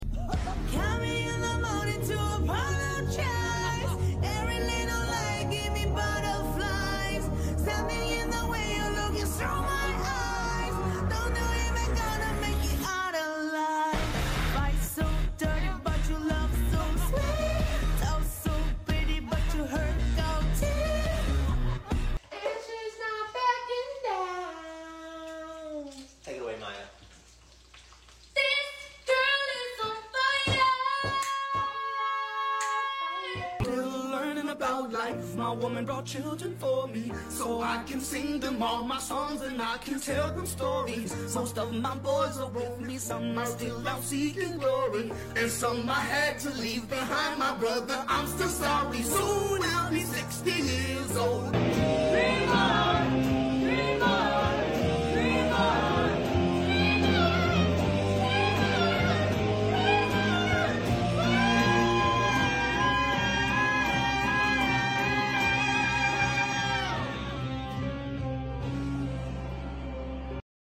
Ranking Top 4 Mindblowing singing sound effects free download